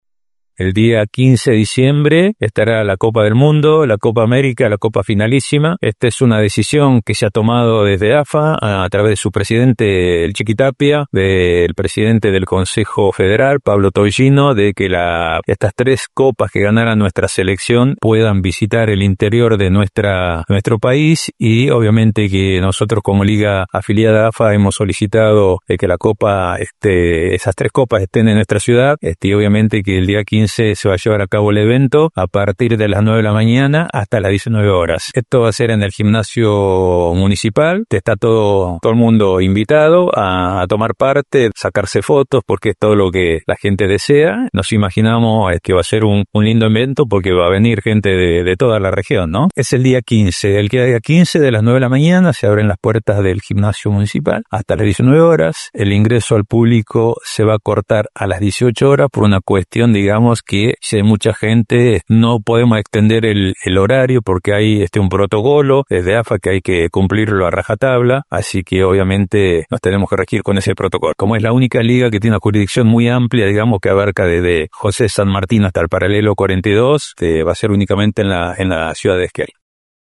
En conferencia de prensa, se anunció que en el mes de diciembre llegarán a Esquel, los trofeos de la Copa del Mundo, la Copa América y la Finalissima.